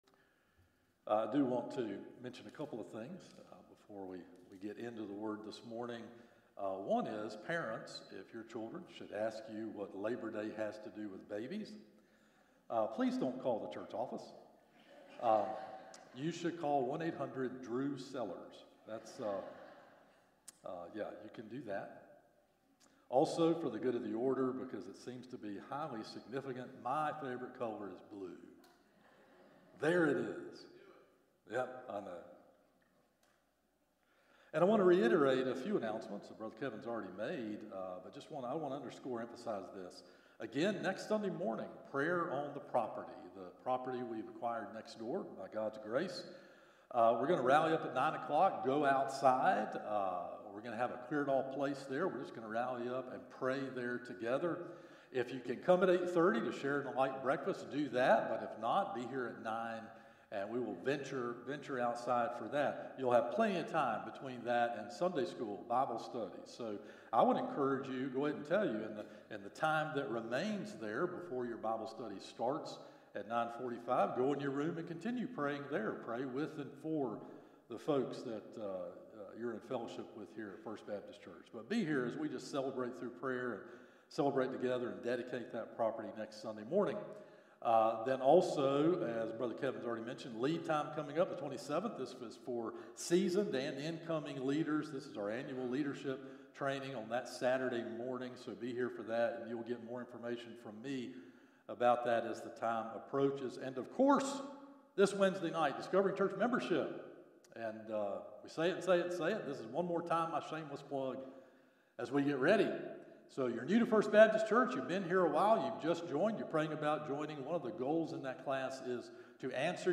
Morning Worship - 11am Passage